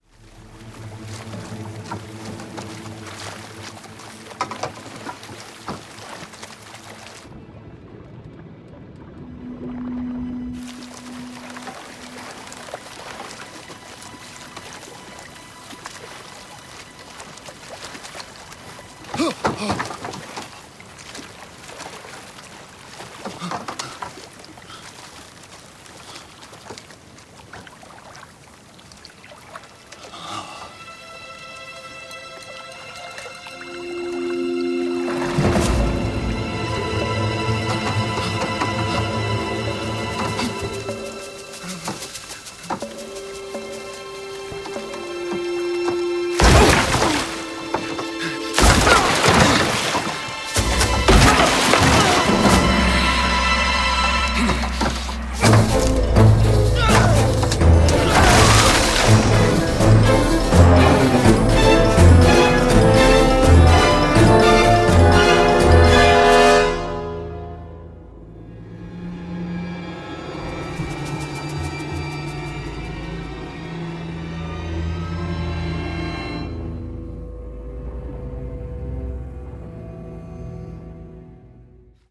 L'homme vogue sur la mer mais un danger est perceptible.